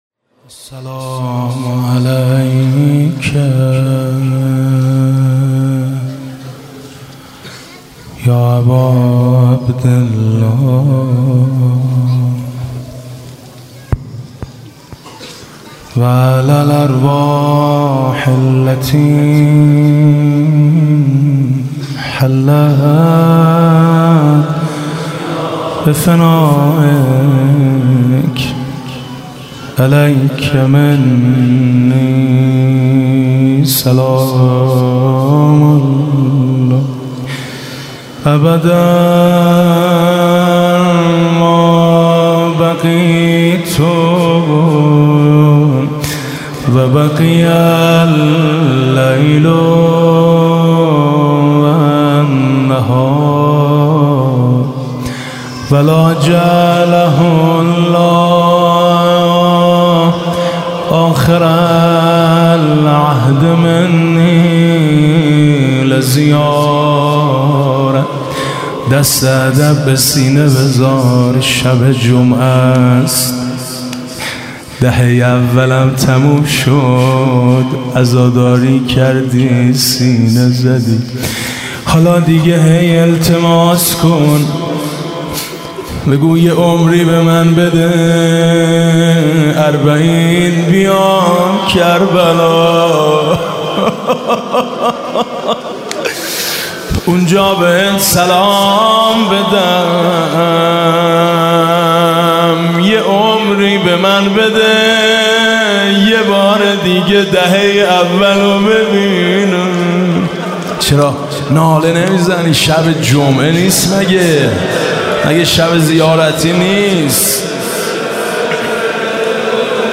«محرم 1396» (شب پانزدهم) روضه: نشسته در عزایش آسمان ها و زمین در خون